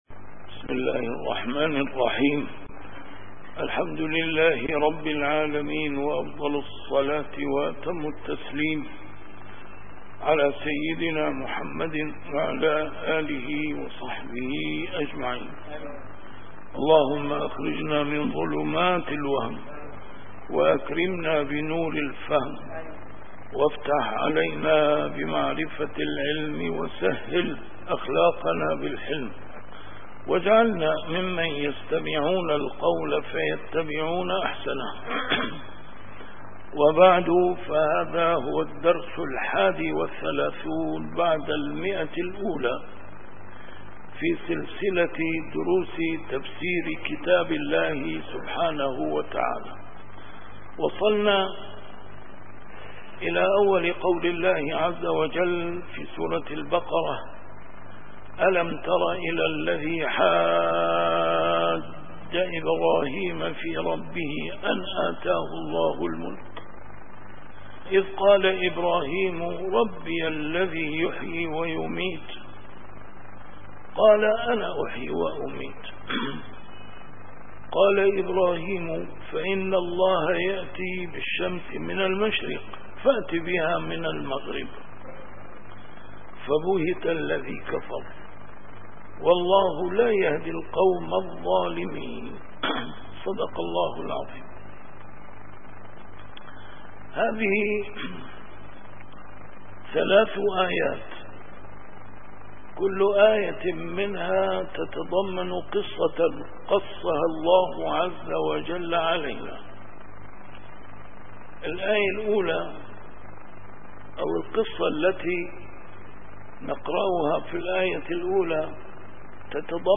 A MARTYR SCHOLAR: IMAM MUHAMMAD SAEED RAMADAN AL-BOUTI - الدروس العلمية - تفسير القرآن الكريم - تفسير القرآن الكريم / الدرس الواحد والثلاثون بعد المائة: سورة البقرة: الآية 258